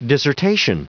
Prononciation du mot dissertation en anglais (fichier audio)
Prononciation du mot : dissertation